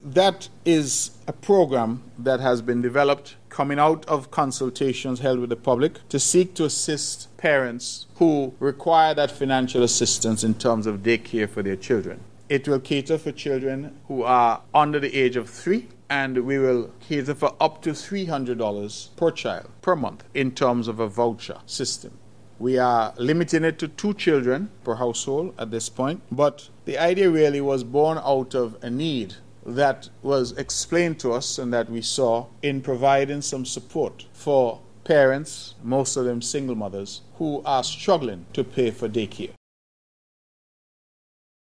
Nevis’ Premier, the Hon. Mark Brantley, made the announcement on August 29th: